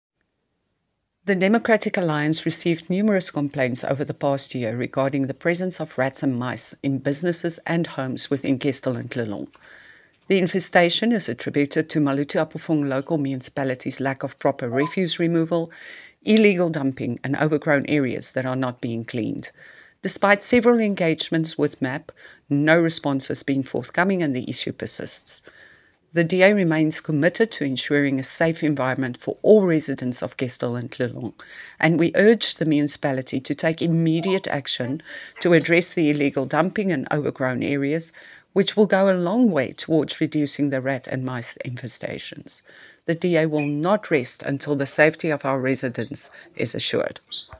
Afrikaans soundbites by Cllr Bea Campbell-Cloete and